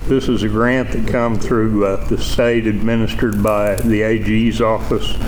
The Board of Osage County Commissioners convened for a regularly scheduled meeting at the fairgrounds on Monday.
District Three Commissioner Charlie Cartwright